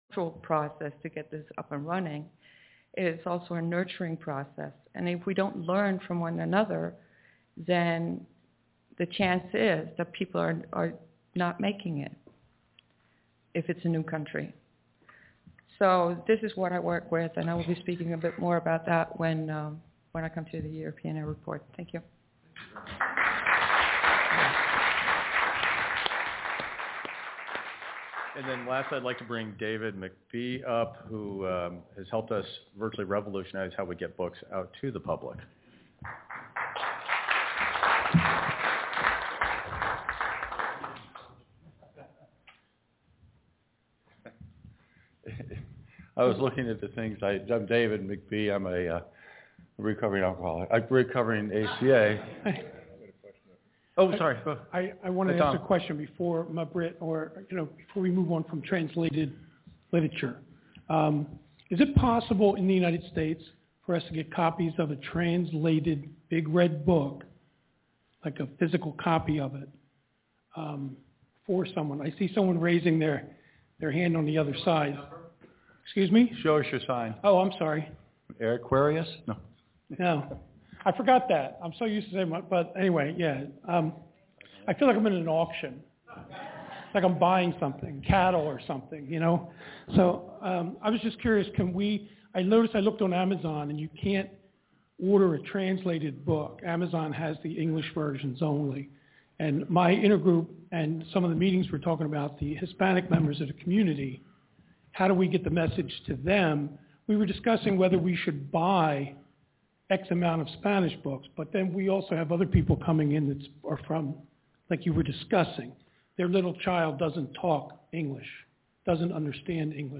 The 2018 Annual Business Conference is being held in Toronto, Ontario, Canada on April 26th and 27th.
The ABC was broadcast on the Standard Teleconference line and the recordings are posted below. Some of the sessions were missed and the sound quality is inconsistent.